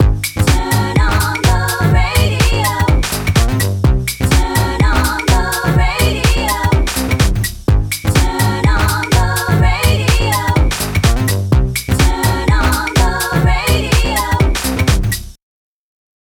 The loop and vocal at this point are not playing nicely together.
The raw, out of tune vocal.